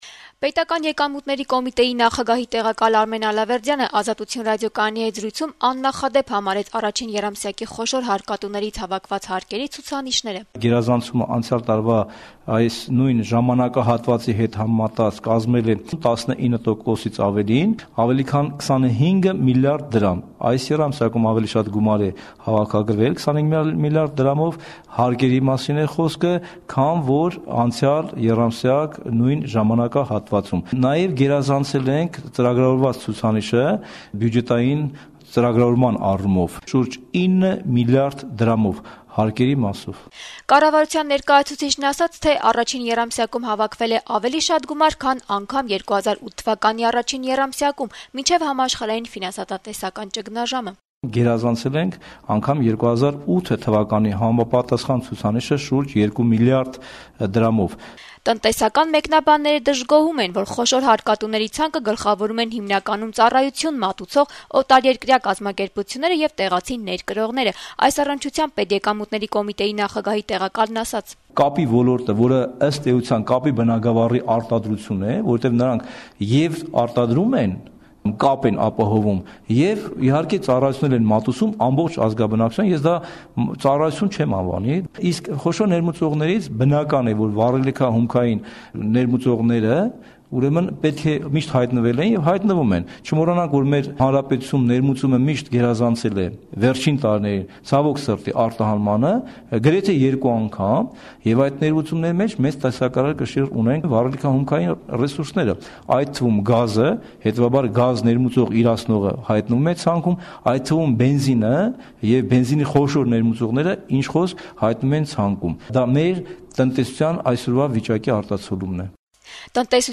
Պետական եկամուտների կոմիտեի (ՊԵԿ) նախագահի տեղակալ Արմեն Ալավերդյանը «Ազատություն» ռադիոկայանի հետ զրույցում «աննախադեպ» որակեց ընթացիկ տարվա առաջին եռամսյակում երկրի խոշոր հարկատուներից հավաքված հարկերի ցուցանիշները: